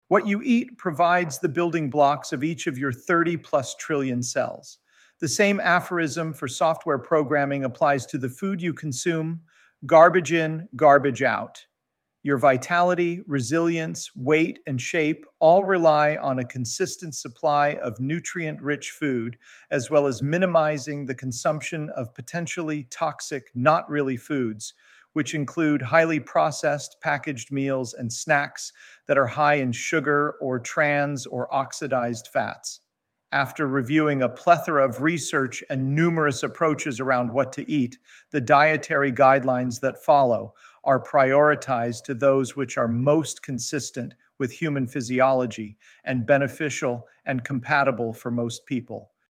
Sample audio EXCERPTS from the audiobooks: